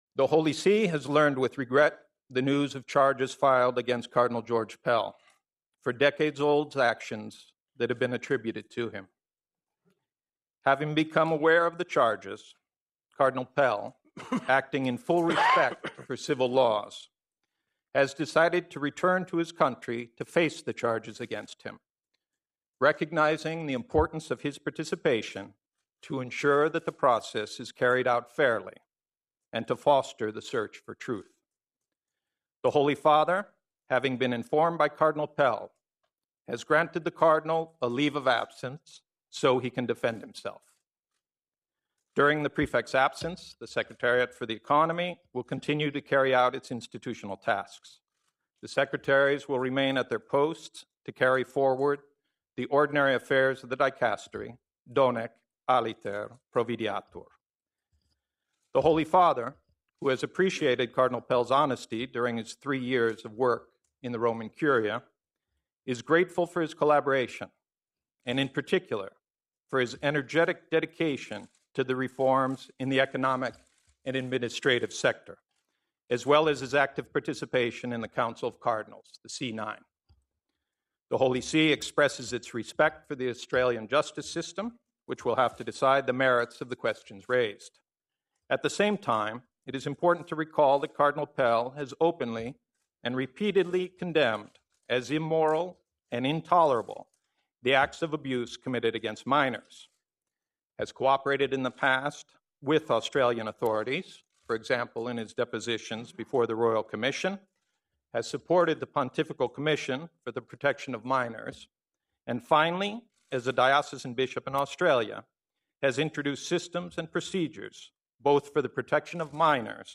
At a press conference Thursday morning at the Vatican, Cardinal George Pell, the Prefect of the Secretariat for the Economy, forcefully denied charges of “historical” sexual offences filed against him by Australian police.